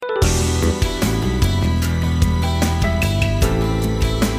romantic-intro_14239.mp3